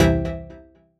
Synth Stab 07 (C).wav